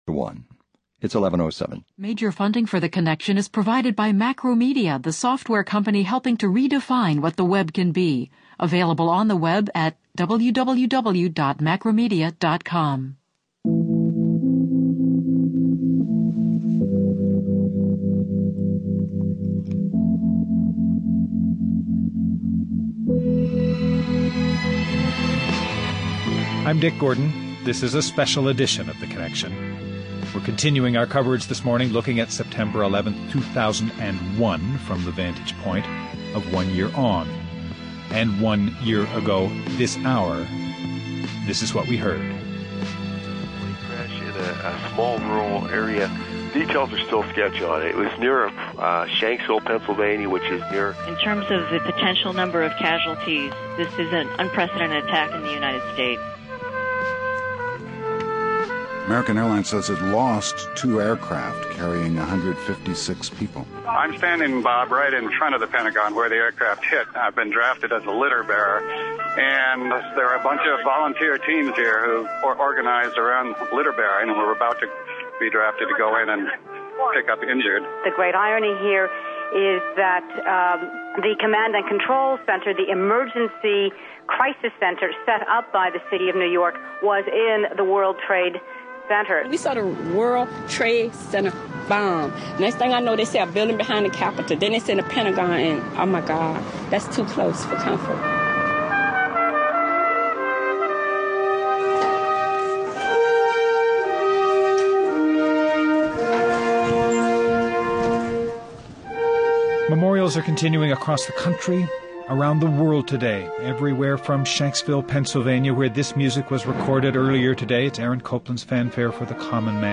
He’s director of Harvard’s Carr Center for Human Rights, and author of a number of books, among then, The Warrior’s Honor, Ethnic War and the Modern Conscience.